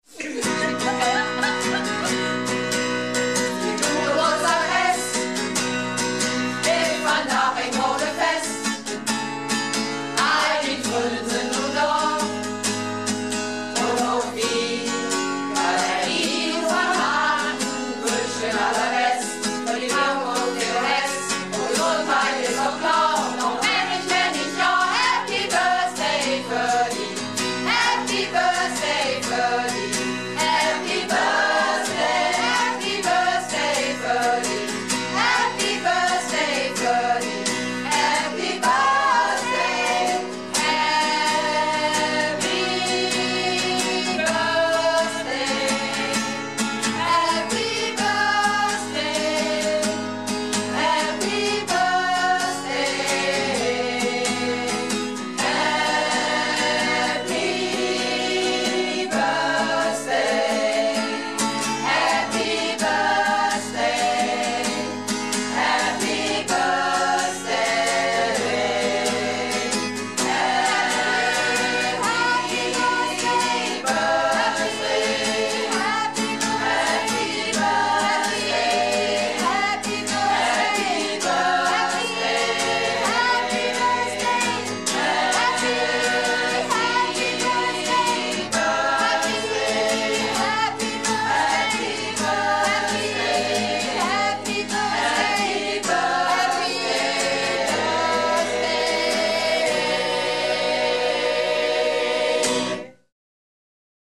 Malle Diven - Probe am 17.12.13 in Pewsum